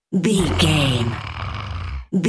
Monster growl snarl small creature
Sound Effects
scary
eerie
angry